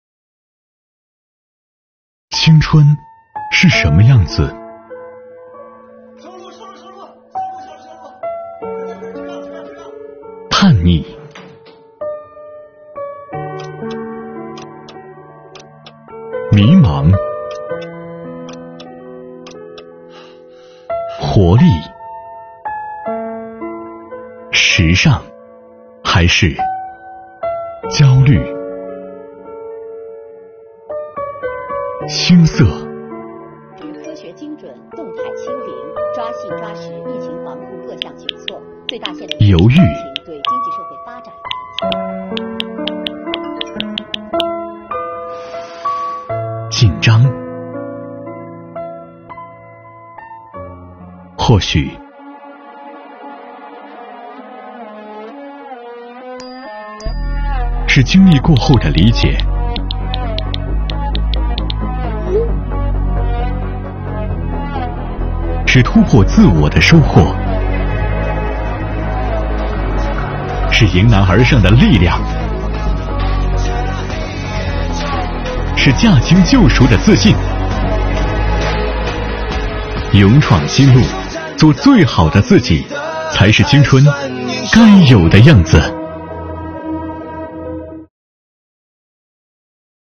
作品前半部分展示出年轻人初入职场面临的焦虑与彷徨，后半部分展现了年轻人勇往直前努力拼搏的精神，形成鲜明对比。背景音乐感染力强，与画面融为一体，充分描绘了在艰苦奋斗中砥砺前行的税务青年，感情真挚，引人共鸣。